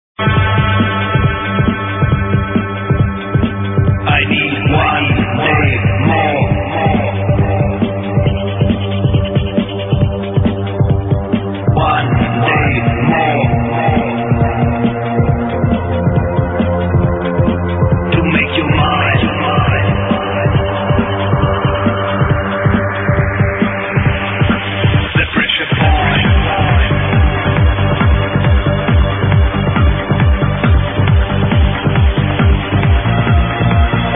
Dark and driving.